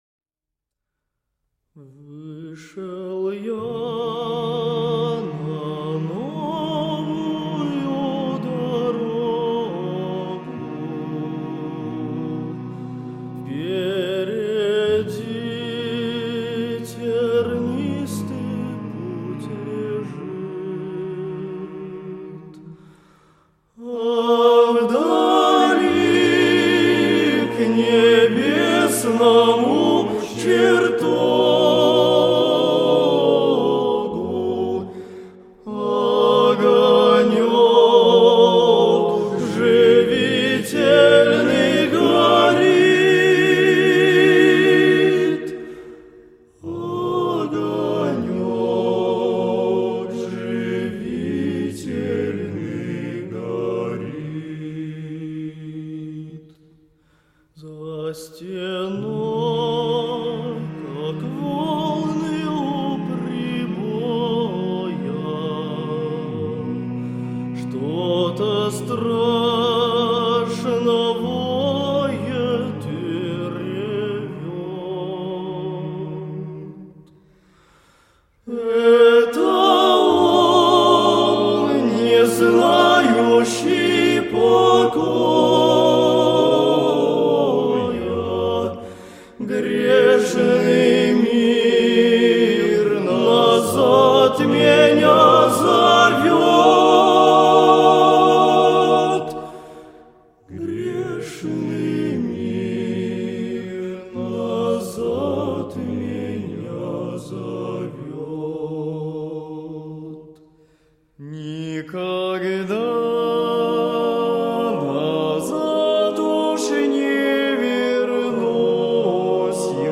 песня
86 просмотров 164 прослушивания 2 скачивания BPM: 75